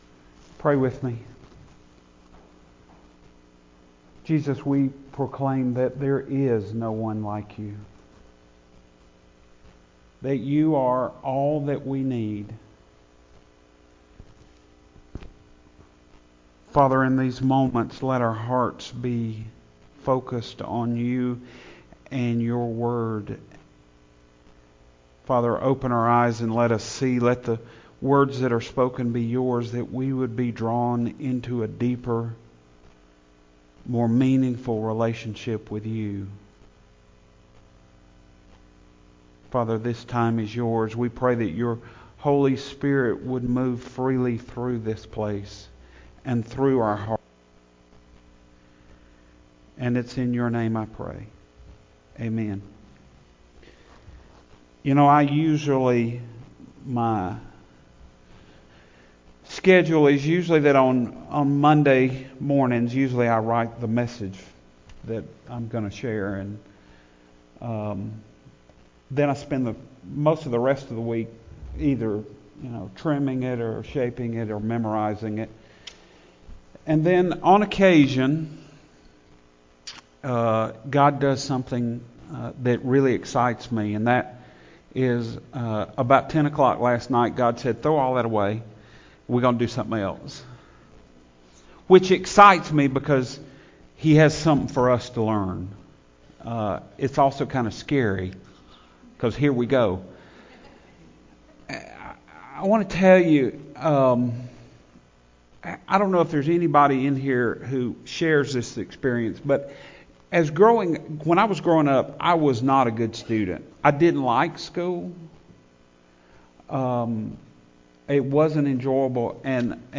Minor Prophets - Major Lessons Sunday Morning Sermon